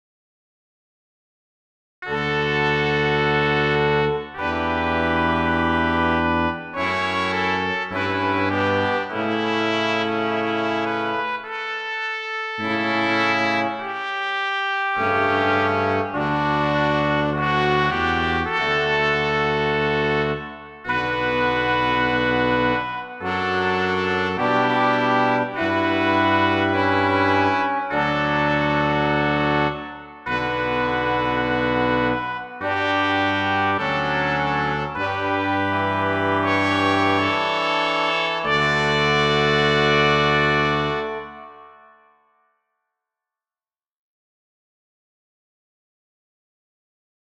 00-Brass-Chorale.m4a